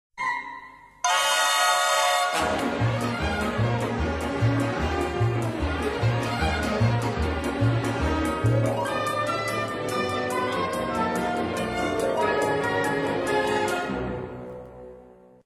Orchestersuite | Ballettparodie